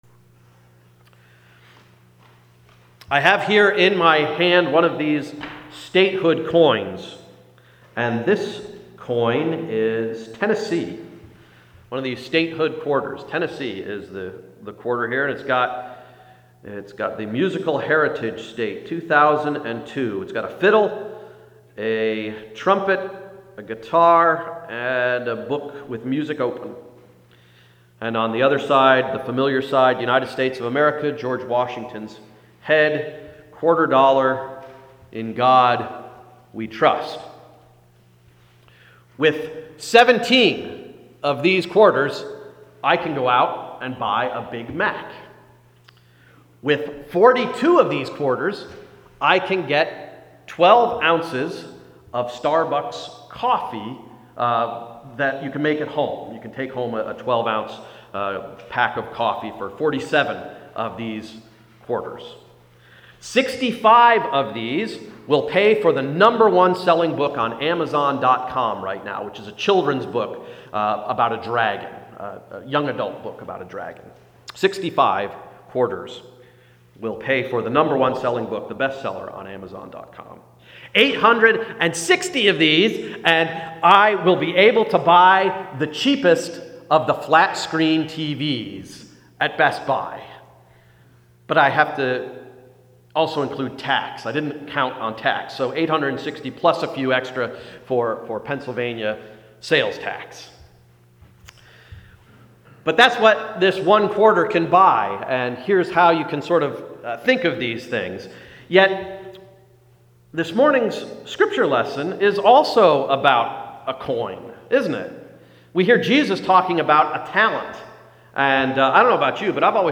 Sermon of November 13, 2011–“God’s Investment Banker”